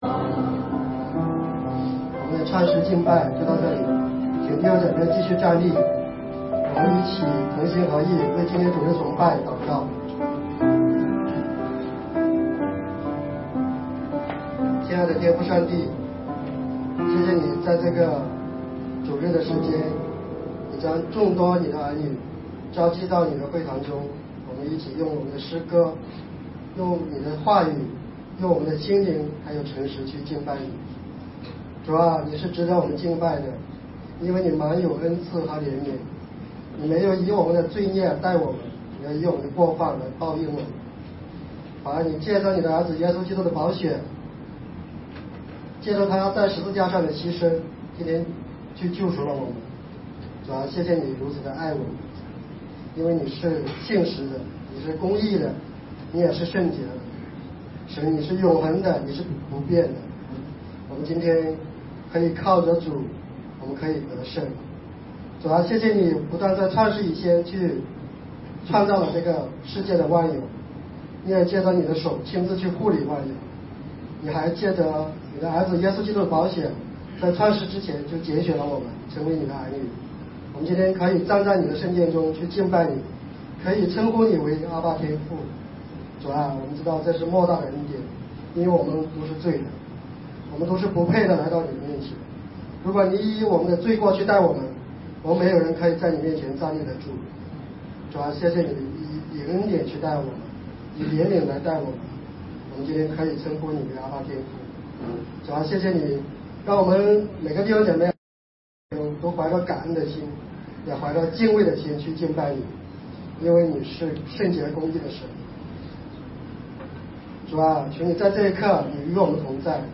雅各书第10讲信心的胜利-等候主再临的忍耐 2016年8月7日 上午11:07 作者：admin 分类： 雅各书圣经讲道 阅读(6.48K